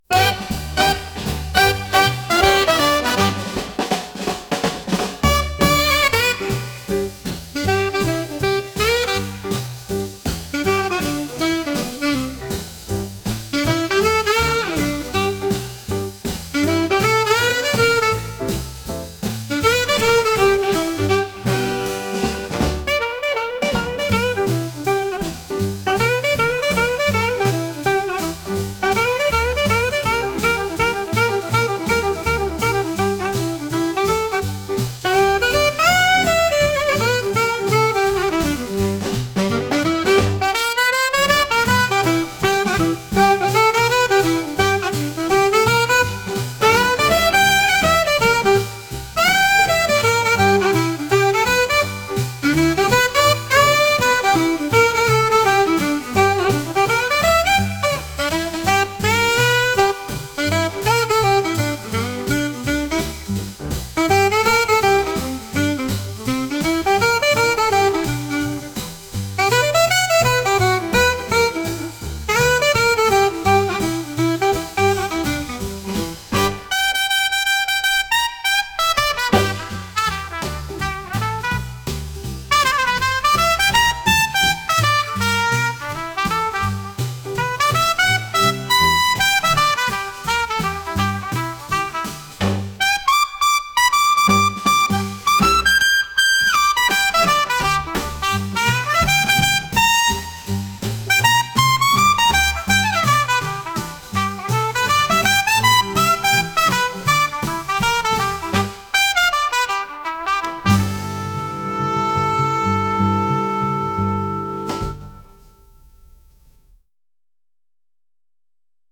都会を感じるような音楽です。